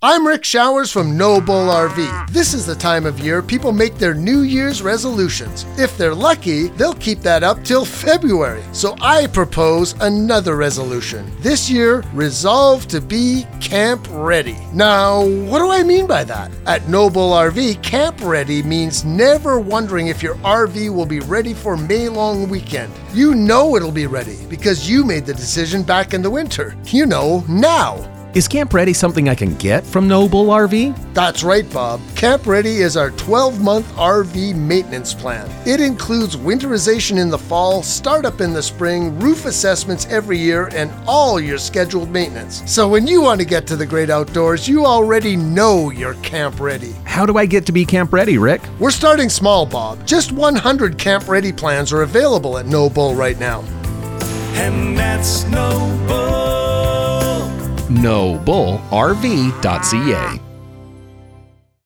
2026 Radio Ads